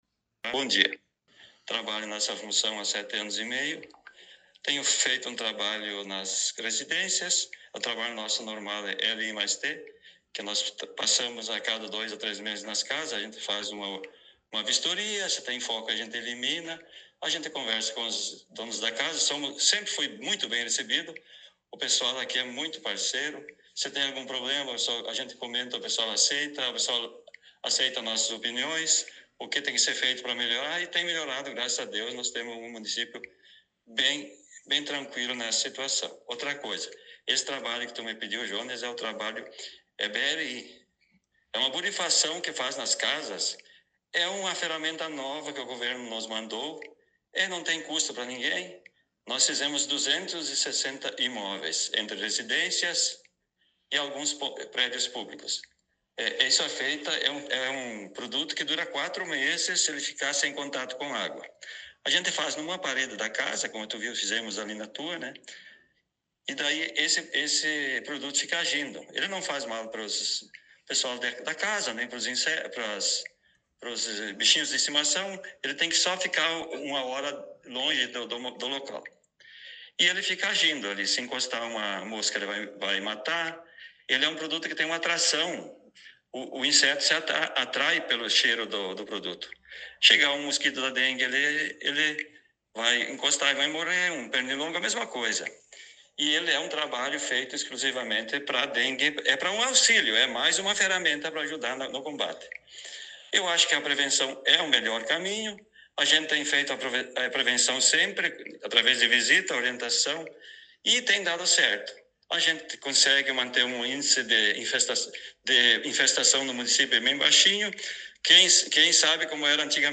Vigilância Sanitária- Agente de Endemias concedeu entrevista